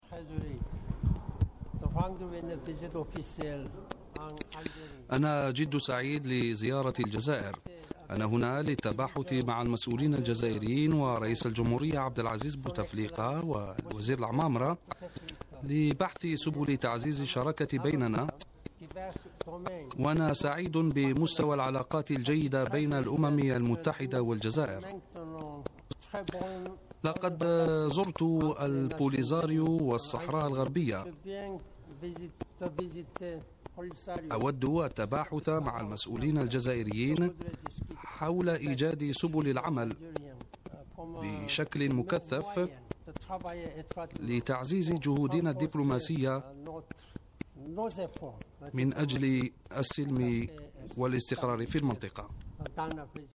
تصريح با كي مون بشأن زيارته إلى العيون المحتلة تصريح با ن كي مون بشأن استئناف روس جولته الدبلوماسية بالمنطقة الأمين العام للأمم المتحدة بان كي مون ( مترجم ) وزير الدولة وزير الشؤون الخارجية و التعاون الدولي رمطان لعمامرة